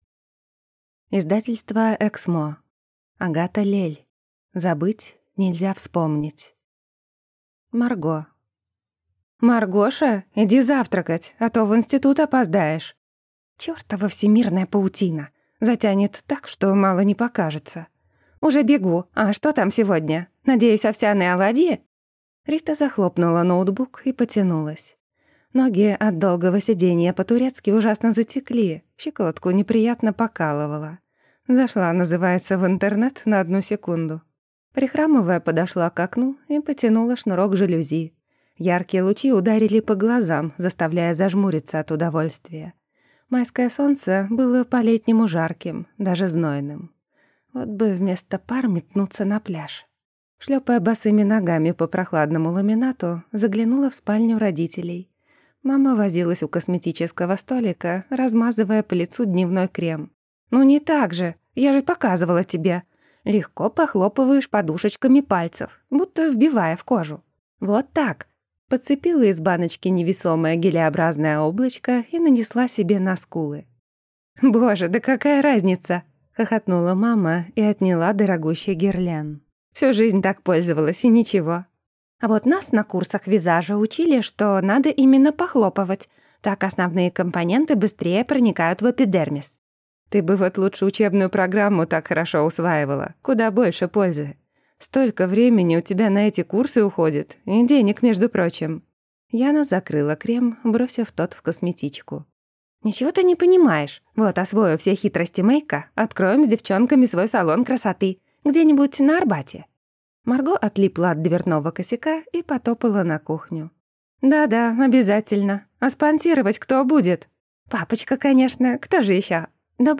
Аудиокнига Забыть нельзя вспомнить | Библиотека аудиокниг